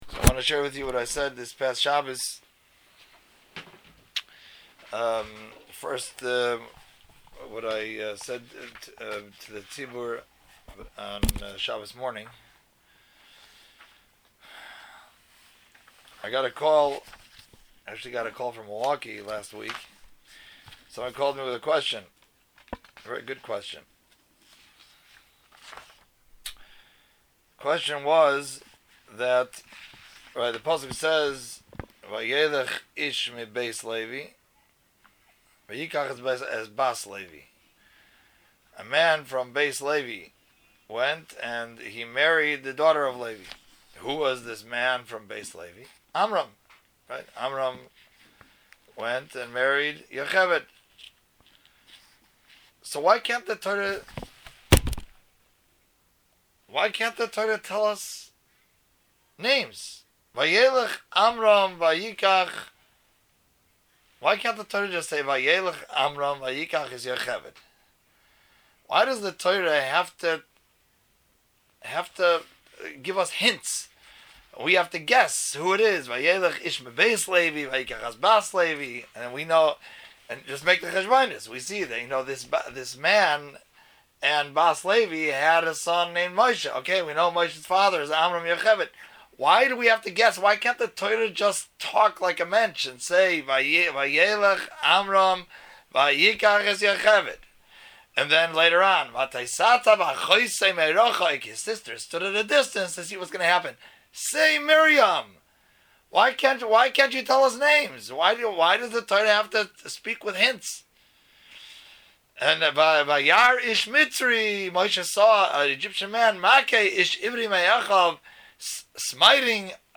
Shmos Drasha-Leave Out the Names